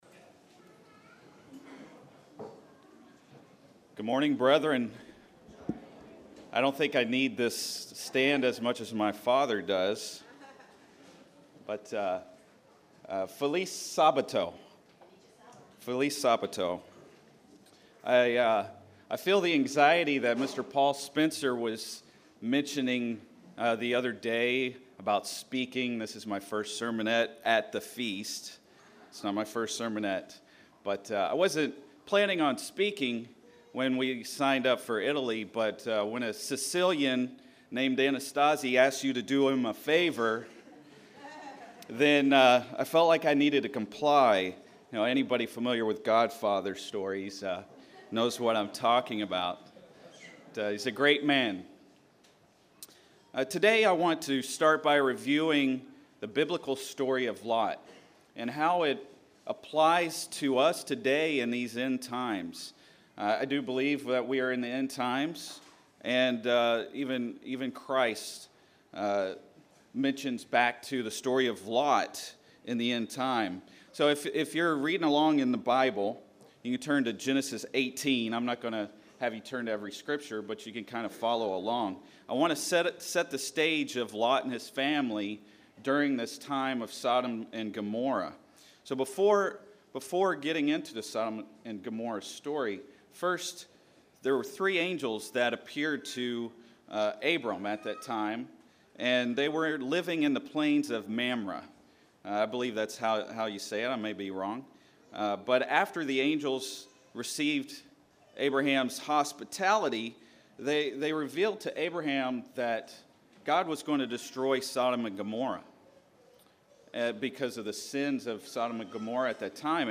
English Message